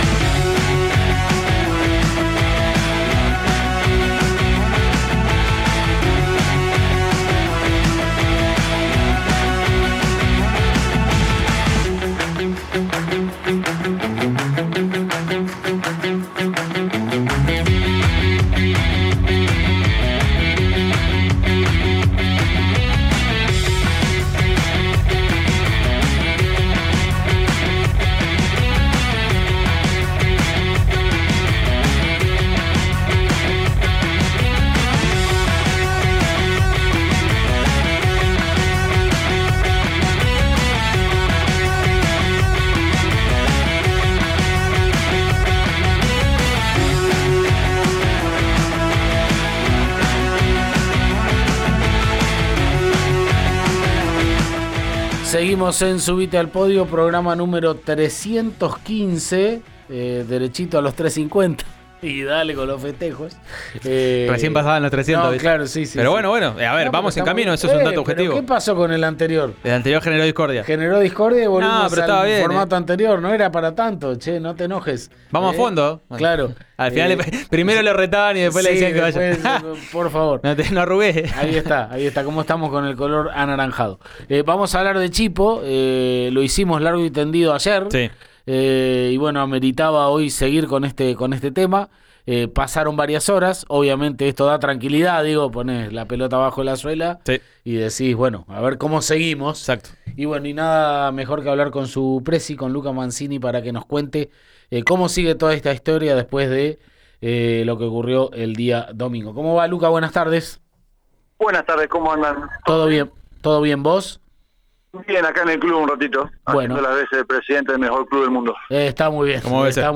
en diálogo con “Subite al Podio” de Río Negro Radio.